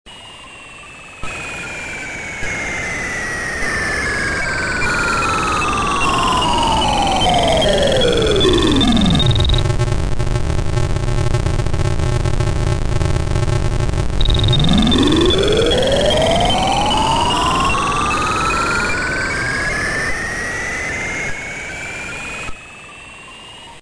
These were all recorded as WAV's and converted to MP3's to save space.
Zzap!! You've been Teleported...
teleport.mp3